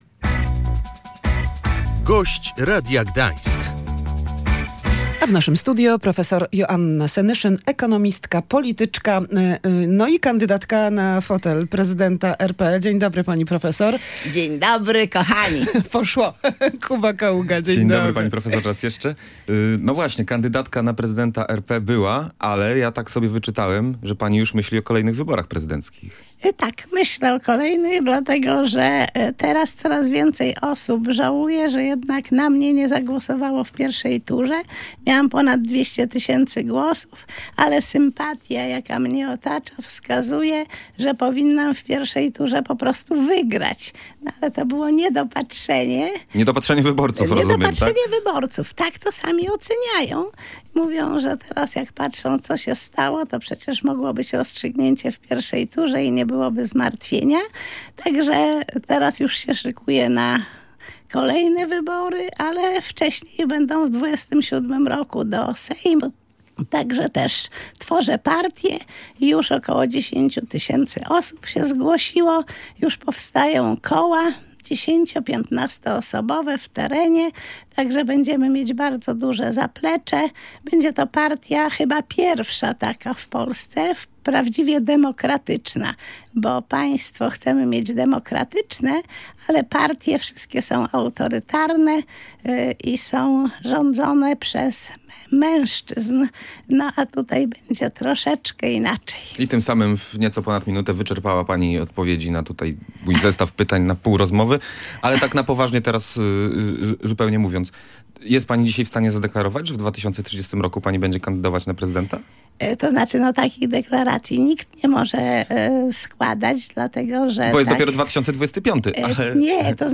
Kończy się era PO i PiS-u w Polsce, więc tworzę własną partię - zadeklarowała w Radiu Gdańsk profesor Joanna Senyszyn.